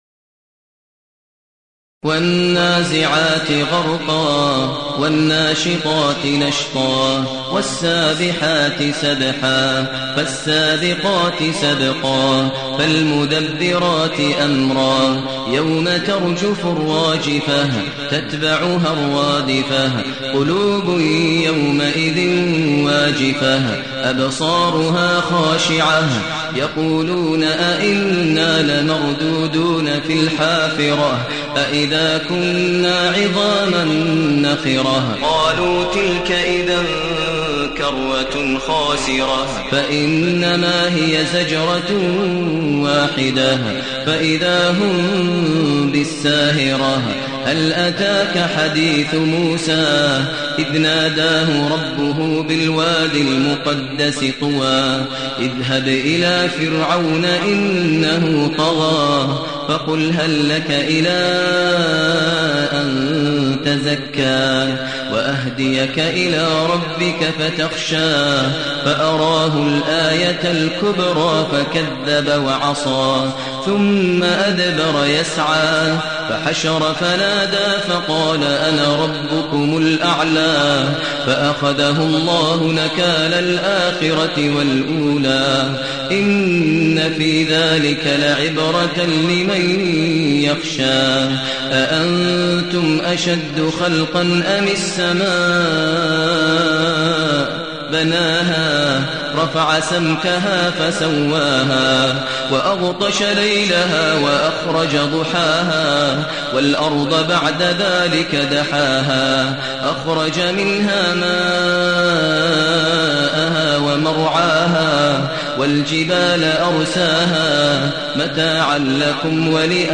المكان: المسجد الحرام الشيخ: فضيلة الشيخ ماهر المعيقلي فضيلة الشيخ ماهر المعيقلي النازعات